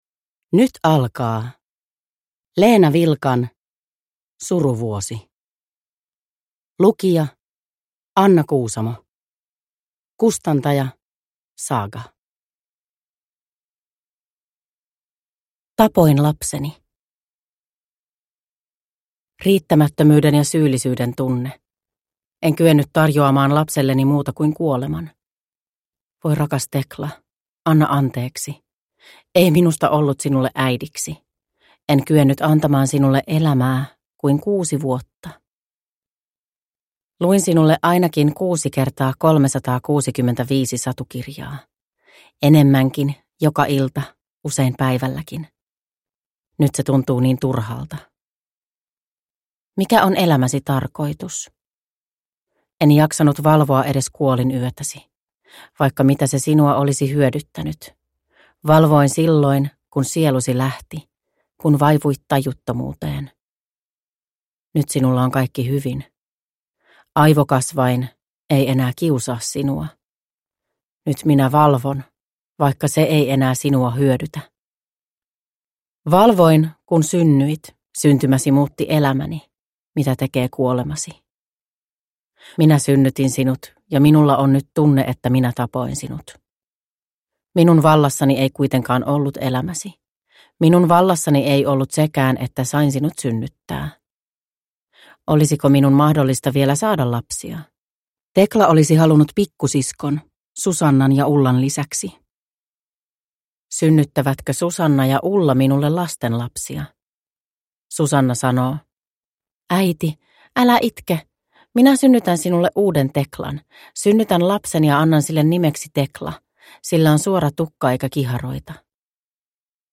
Suruvuosi (ljudbok